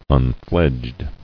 [un·fledged]